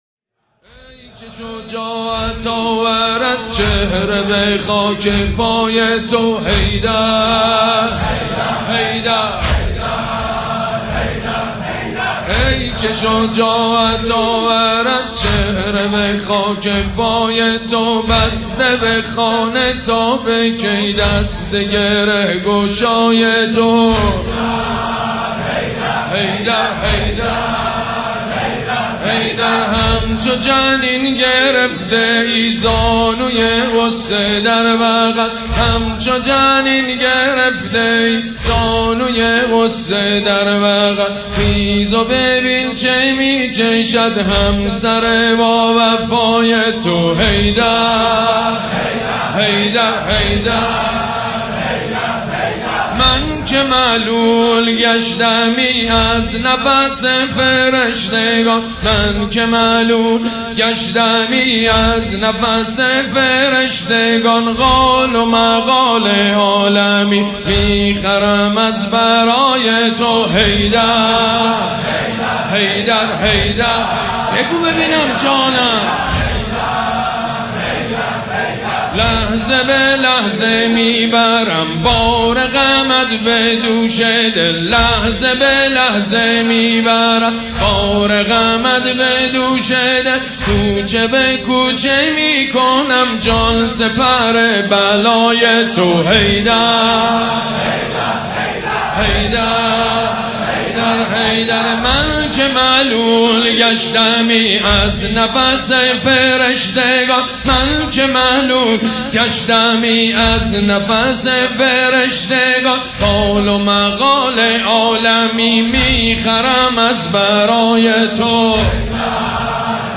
عنوان : واحد فاطمیه